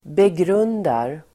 Uttal: [begr'un:dar]